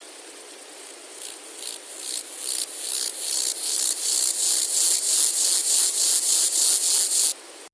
Акустические сигналы: одиночный самец, Россия, Горный Алтай, Чемальский район, окрестности поселка Элекмонар, запись
Температура записи 30-35° С.